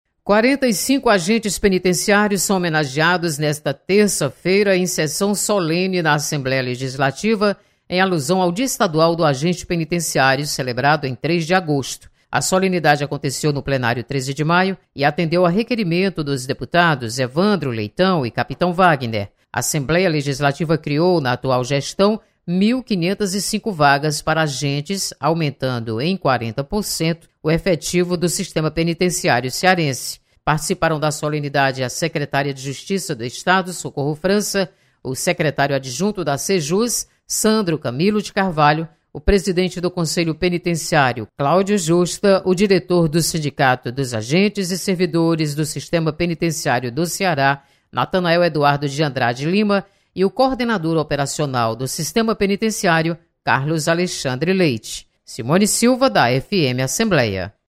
Assembleia homenageia agentes penitenciários. Repórter